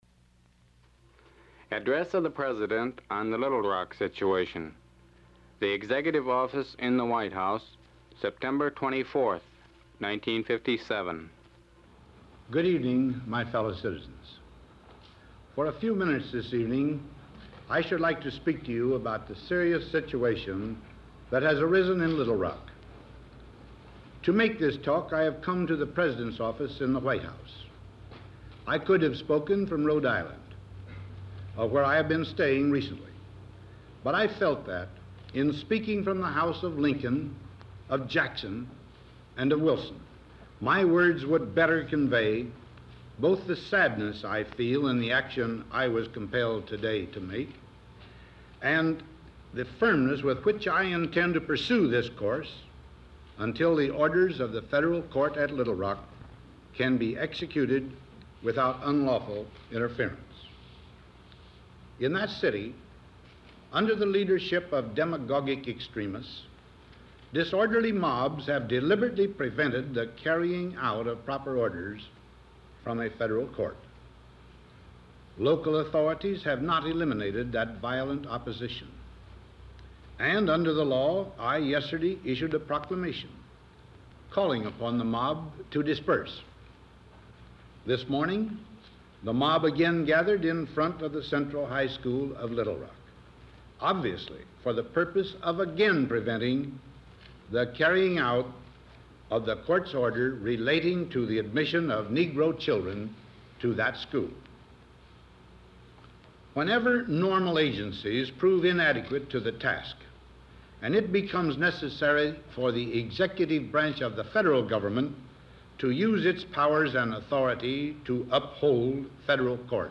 President Eisenhower adressed the American people regarding the school desegregation situation happening in Little Rock, Arkansas.